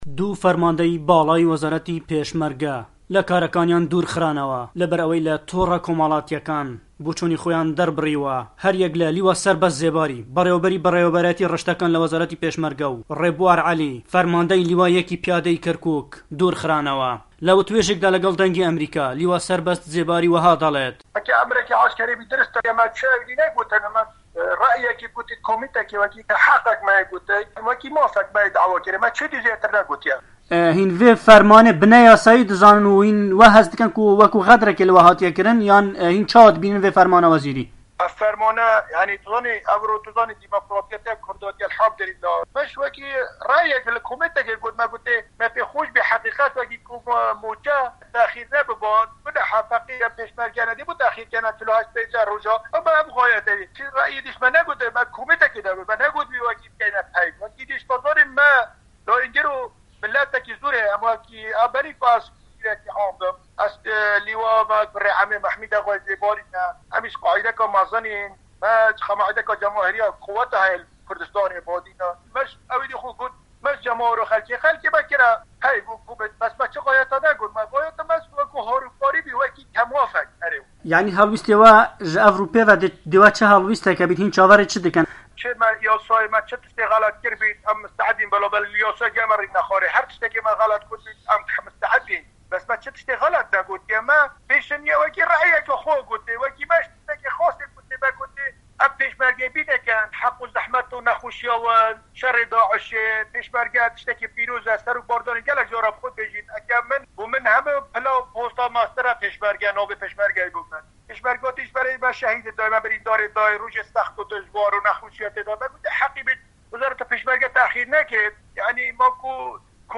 ڕاپـۆرتێک لە بارەی لادانی دوو فەرماندەی پـێشمەرگە لە کارەکانیان